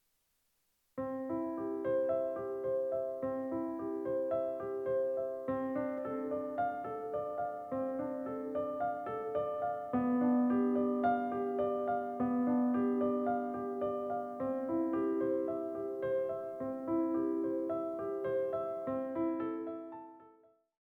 The first was harmonious, the second sounded completely out of tune.